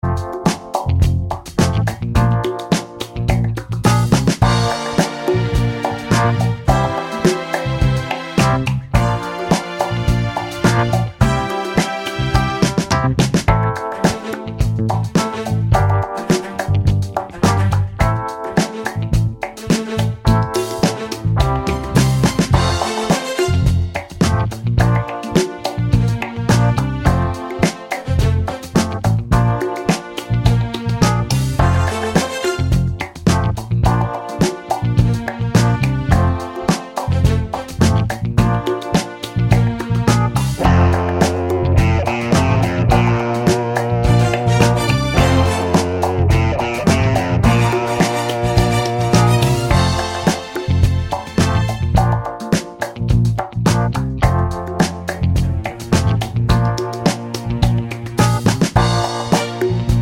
no Backing Vocals Disco 4:14 Buy £1.50